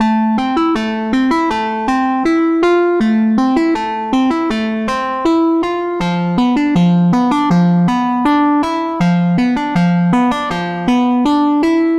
Tag: 80 bpm Chill Out Loops Synth Loops 2.02 MB wav Key : A